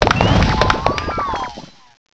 sovereignx/sound/direct_sound_samples/cries/chi_yu.aif at 4adc2dcb16dbe1a6fd2e5684e3a0ae64ba6763c7